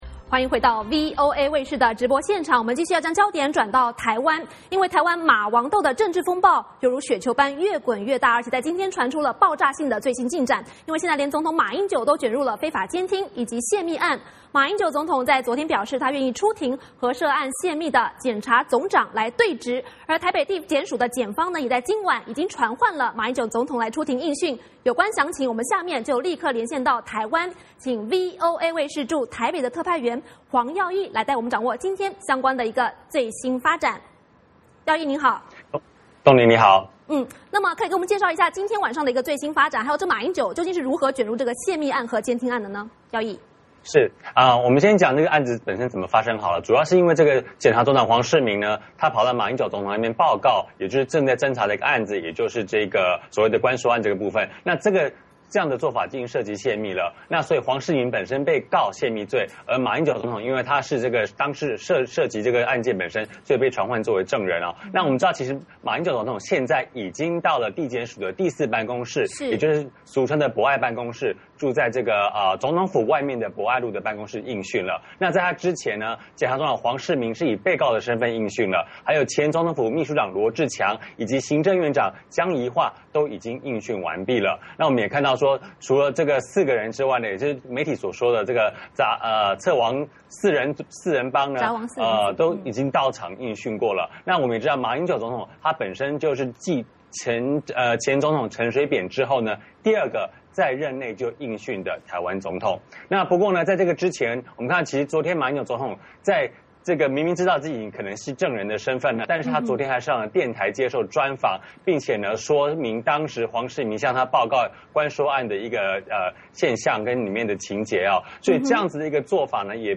VOA连线：台湾总统马英九就泄密案出庭应讯